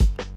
Hihat 1 Wilshire.wav